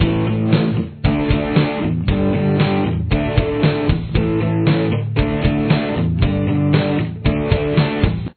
Guitar 2